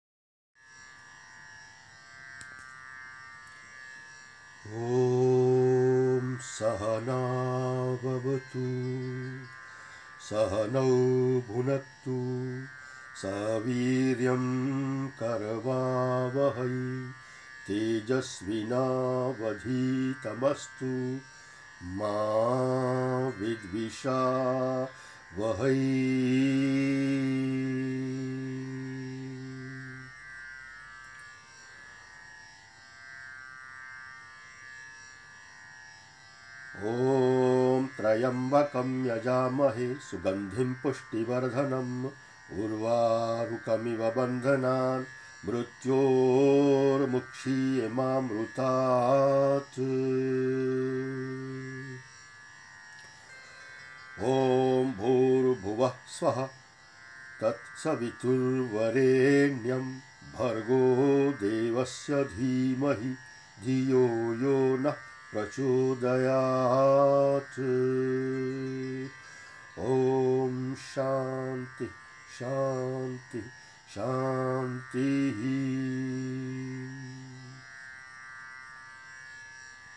Enregistrement 3 chants: Om Sahana, Tryambakam, Gayatri
Dans l’ordre: Om Sahana, Tryambakam, Gayatri Mantra. Les 3 chant récités selon la tradition de Kaivalyadham.